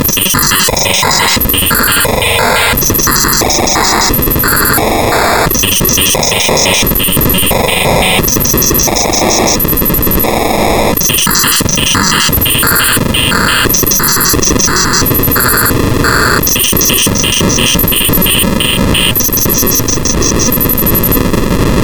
Got ¹ working locally with ², though I've only tried it solo so far - for remote collaboration there would need to be a way to sync `t` between clients, preferably automagically but failing that I can extend Barry with a command to reset `t` to a given value, which in Troop should execute (very approximately) simultaneously on all clients.
Currently playing with some simple rhythmic space: